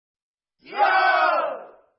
喝彩.mp3